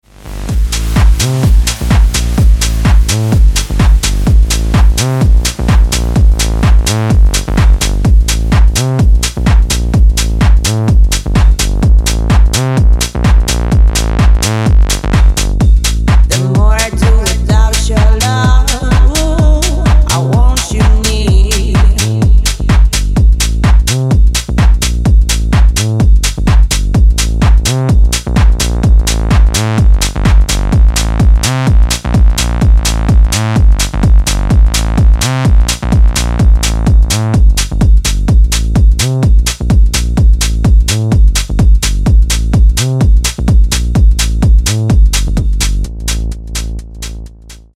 EDM
Bass House
качающие
клубняк
Стиль: bass house